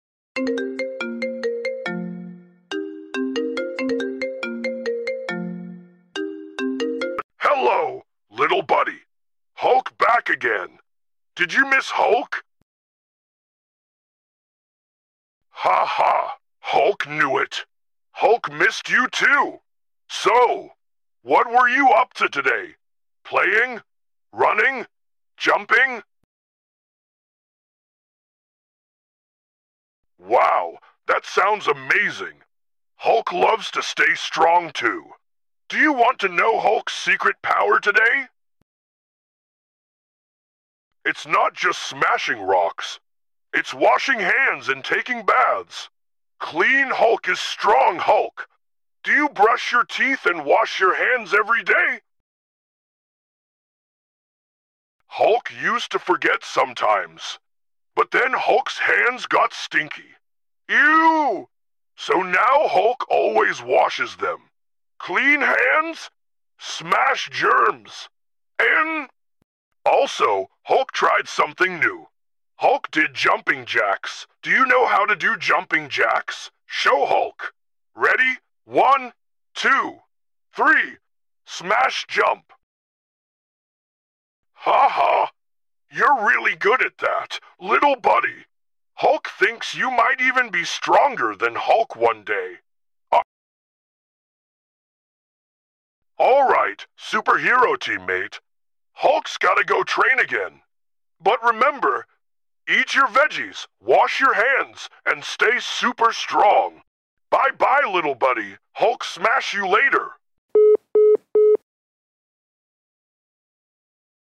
💪 What happens when Hulk talks to kids about being strong, eating veggies, and staying healthy? 😂🥦 Watch this funny fake call prank that kids will love!